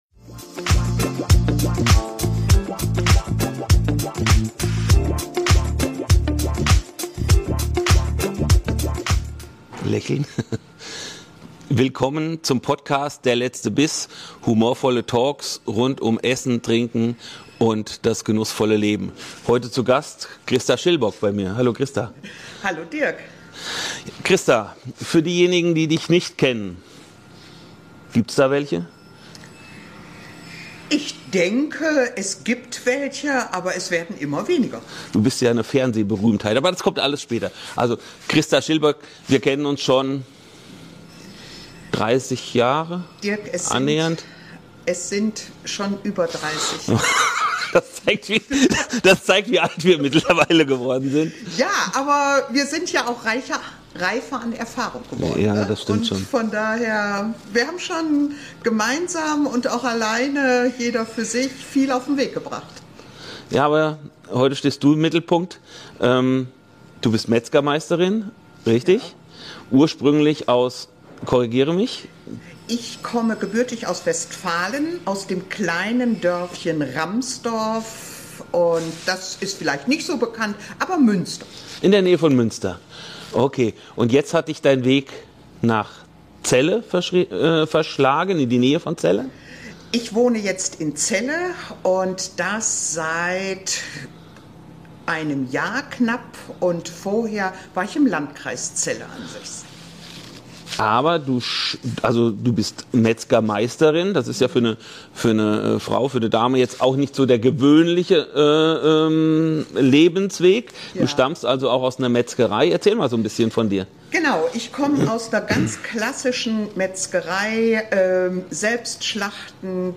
Direkt und neugierig. Es entsteht ein persönliches Gespräch über Leidenschaft, Durchhaltevermögen und die Freude am Kochen. Du bekommst ehrliche Einblicke in die Welt einer TV-Kochshow und in den Weg einer Köchin, die ihren eigenen Stil gefunden hat.